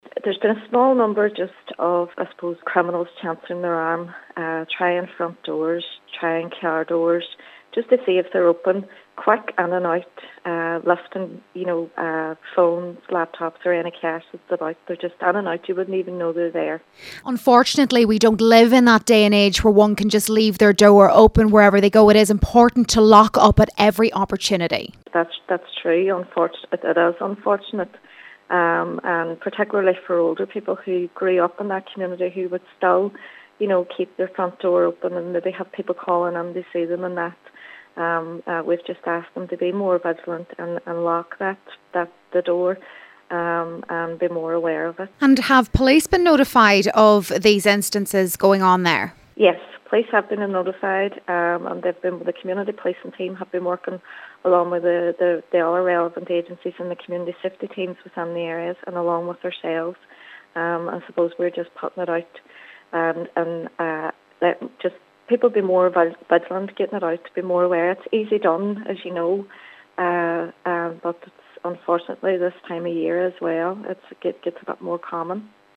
Foyle MLA Karen Mullan is appealing to residents to be extra vigiliant and report any suspicious activity to the police.